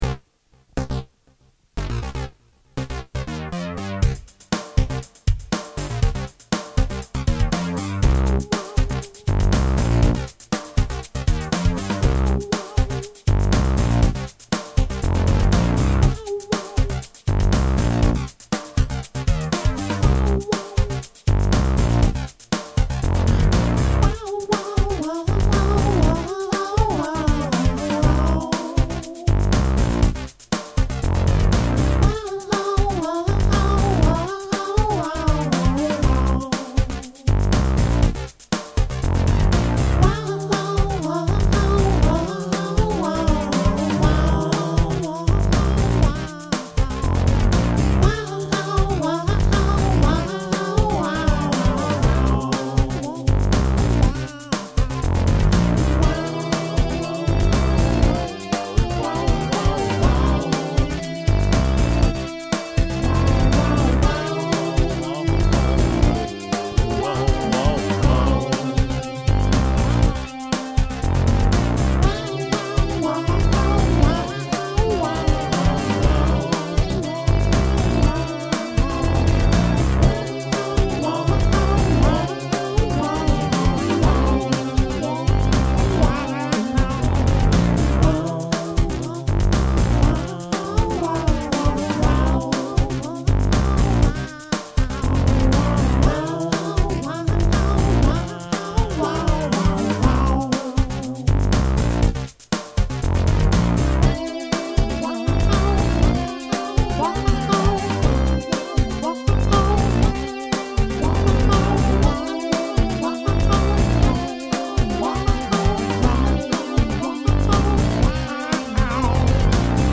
I set out to rerecord ‘God and Ducks’ but instead made a new track, ‘Seven-Eleven Cherry Apple’, which is a more-or-less instrumental piece and another epic testament to my own silliness and lack of shame. It’s funky and silly and not to be played over open speakers in an office.